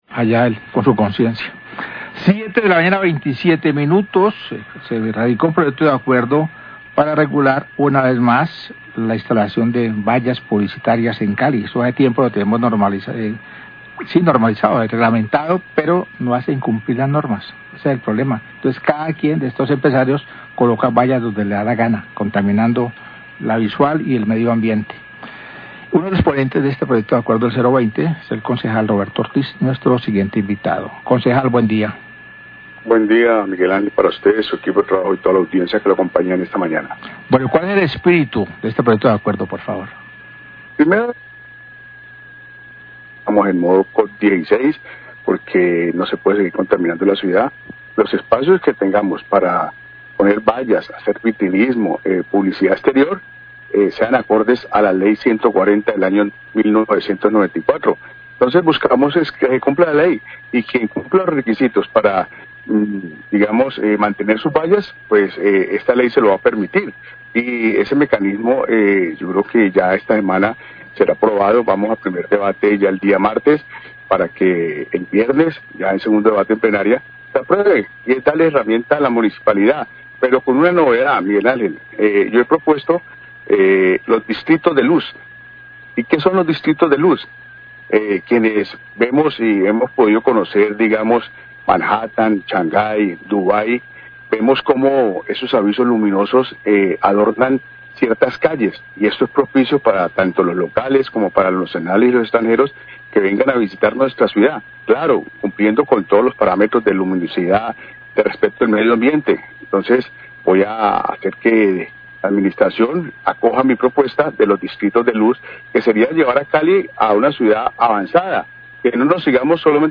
Radio
Se radicó el proyecto de acuerdo para regular las vallas publicitarias en Cali. El ponente del proyecto, el concejal Roberto Ortiz, explicó en qué consiste y habló acerca de la propuesta de construir en la ciudad el distrito de luz.